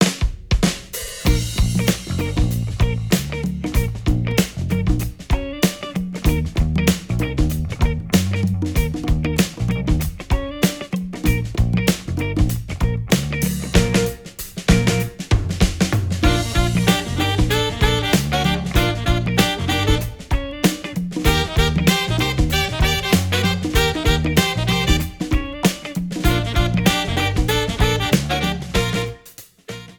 Trimmed, normalized and added fade-out.